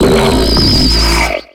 Cri de Limonde dans Pokémon X et Y.